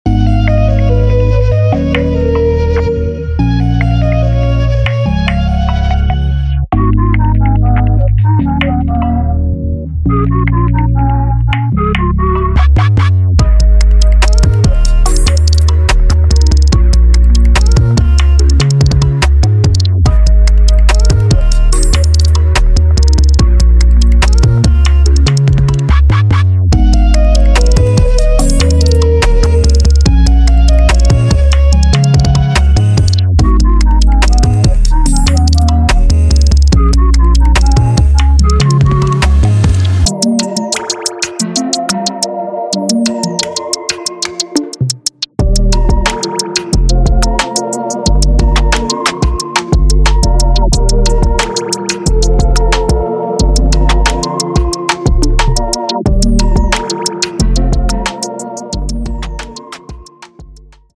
您将听到诡异的旋律、灼热的贝斯线、强劲有力的鼓声、新鲜的陷阱风格的合成器主题等等！